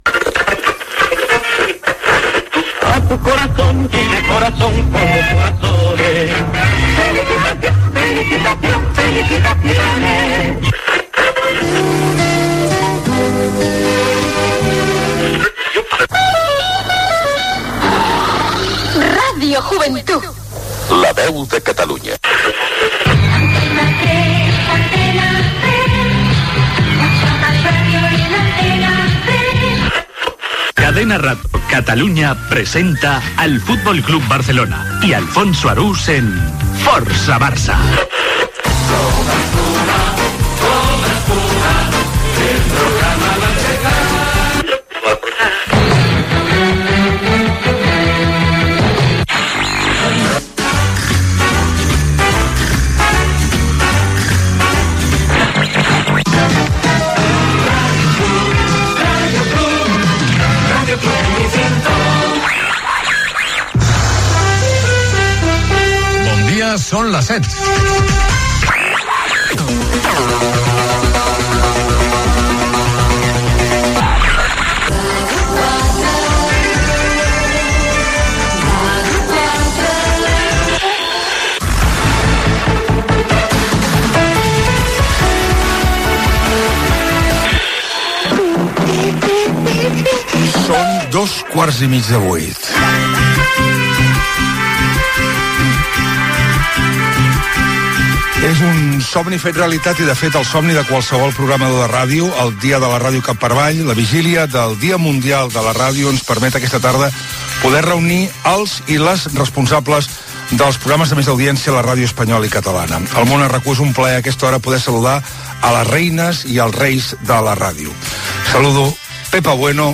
Conversa amb Pepa Bueno, Carlos Herrera, Carlos Alsina i Mònica Terribas, presentadors dels programes matinals de la SER, COPE, Onda Cero i Catalunya Ràdio. Parlen de com fan els seus programes i dels horaris de la seva feina
Info-entreteniment